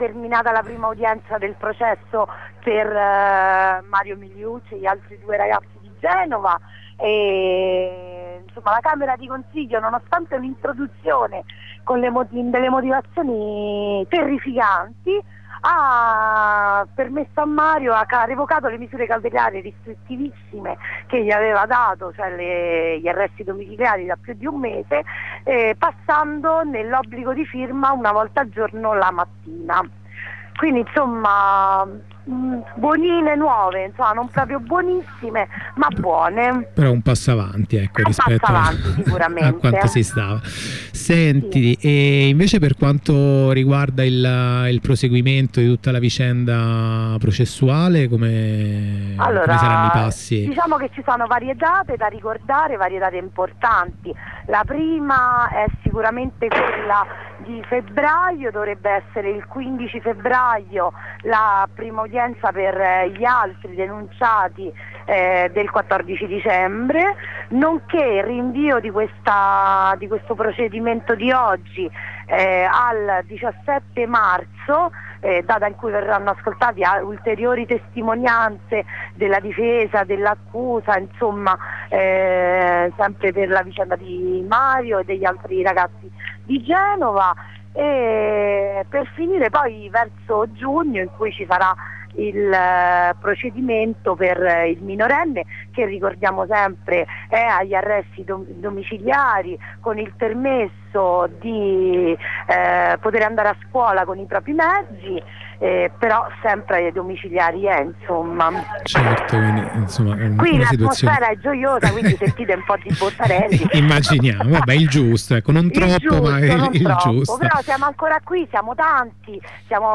Ascolta le corrispondenze dal presidio di solidarietà di piazzale clodio, prima e dopo il pronunciamento del giudice